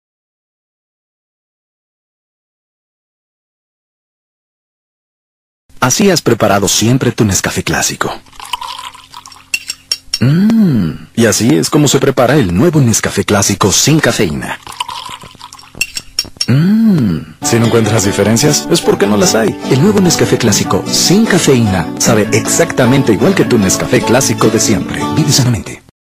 Los efectos de sonido deben evocar lo que consigue la televisión, pero sin ver nada.
Este es un ejemplo de cómo Nestlé publicitó su café descafeinado de Nescafé en la radio mexicana en 2013.